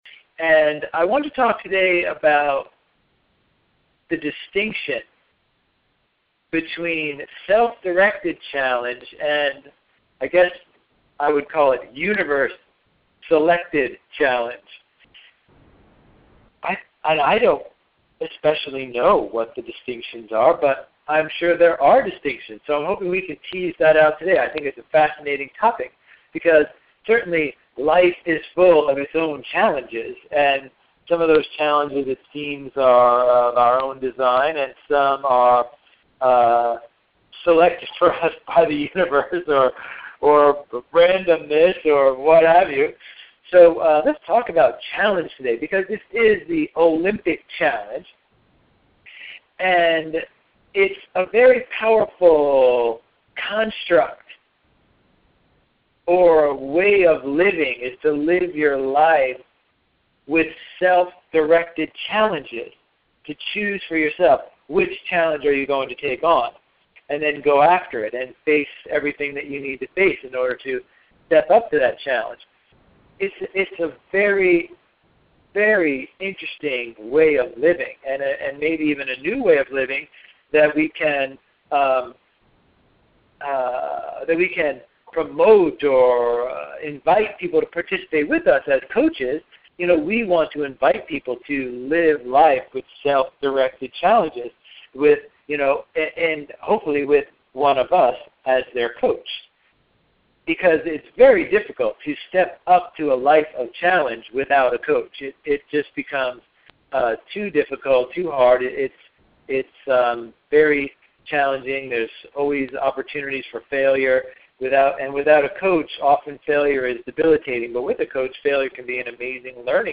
Many deep thoughts and experiences were shared by the extraordinary individuals on the call. Audio Links This audio was recorded on August 3, 2012 from the Midpoint call of our 16-Day Olympic Challenge on LifeVille. This audio is 50 minutes edited out of a 100 minute call.